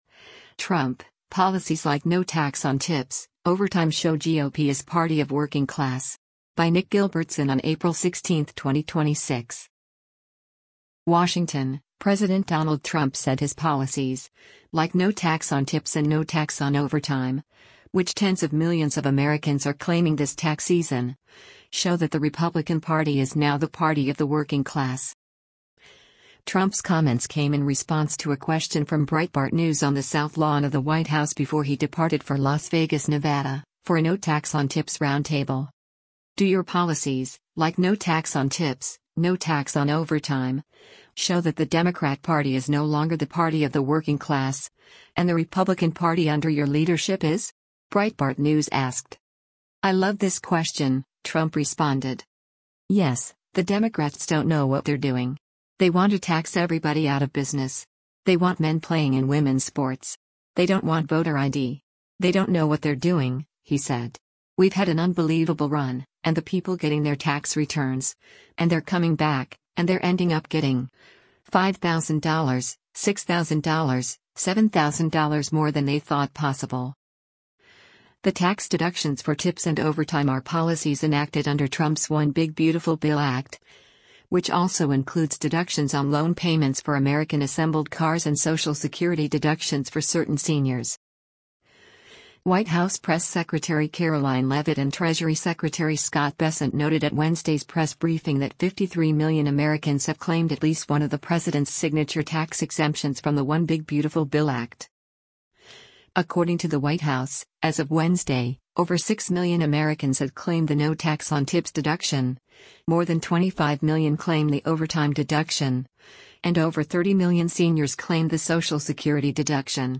Trump’s comments came in response to a question from Breitbart News on the South Lawn of the White House before he departed for Las Vegas, Nevada, for a No Tax on Tips roundtable.